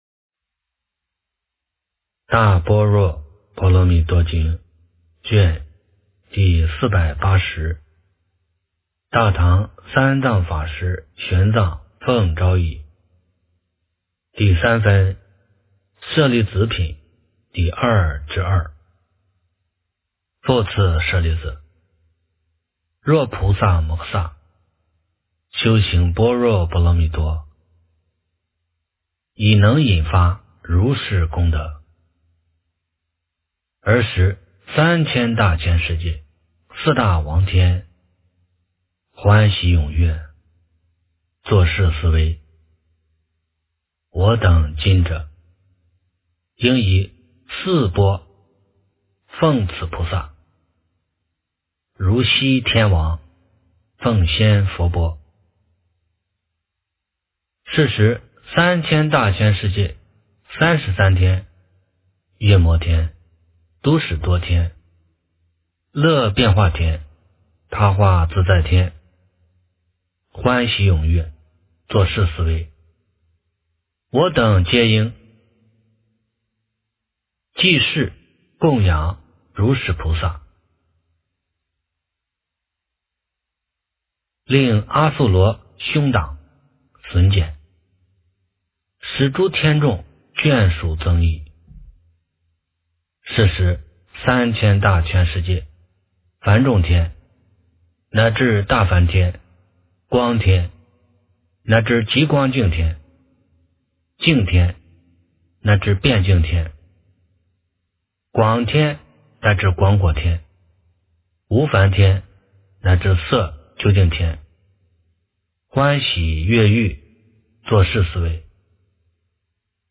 大般若波罗蜜多经第480卷 - 诵经 - 云佛论坛